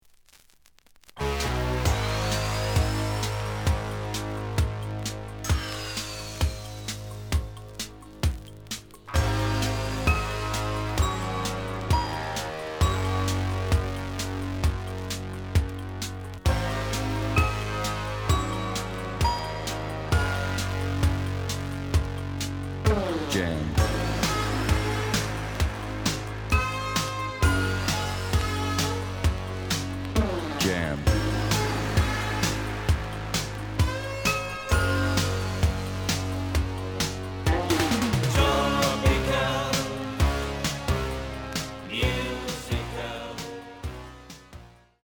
試聴は実際のレコードから録音しています。
The audio sample is recorded from the actual item.
●Genre: Disco